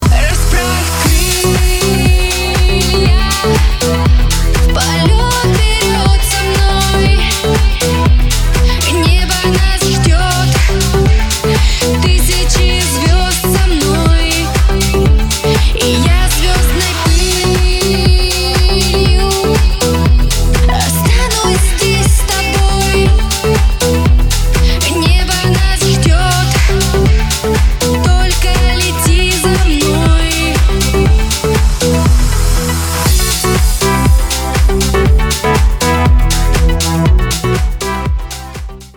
• Качество: 320, Stereo
поп
dance
красивый женский вокал
house